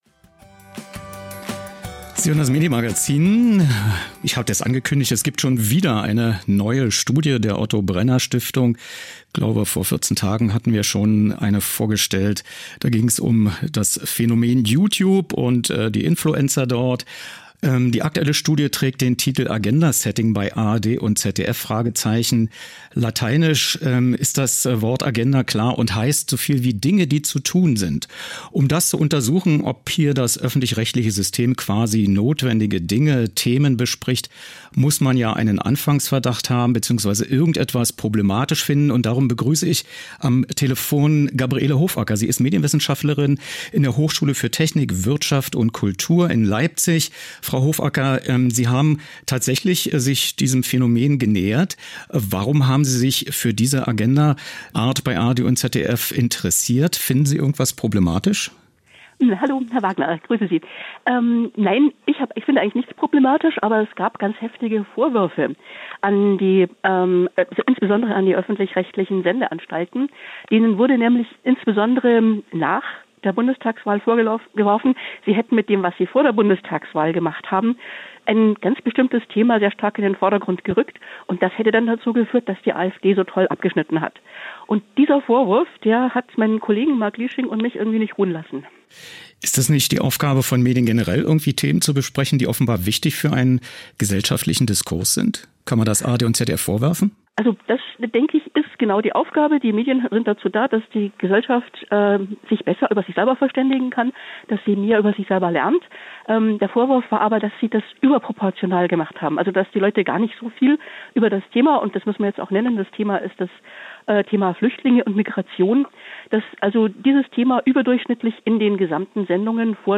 Was: Telefoninterview zur OBS-Studie „Agenda-Setting bei ARD und ZDF?“
* O-Ton: Dr. Peter Frey, ZDF-Chefredakteur am 04.10.2017, Berlin
Wo: Potsdam < --> München